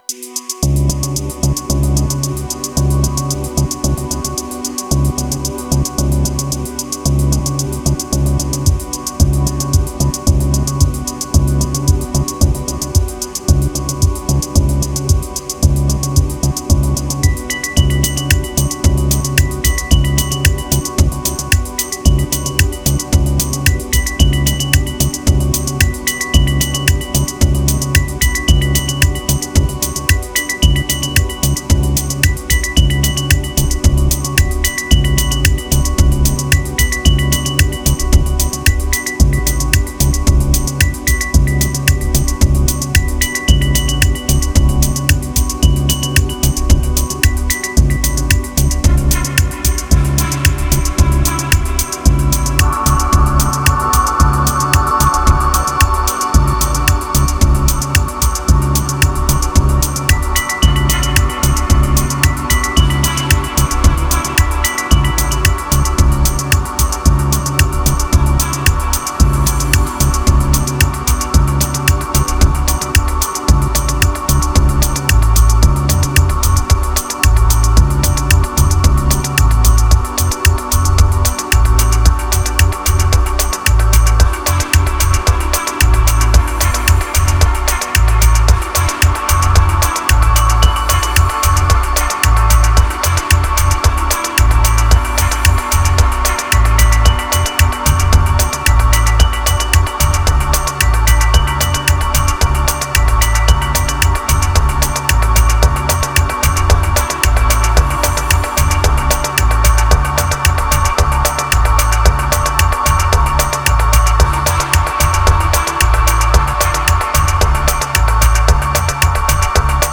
819📈 - 47%🤔 - 112BPM🔊 - 2020-11-09📅 - 463🌟
Tanz Kicks Deep House Dark Epic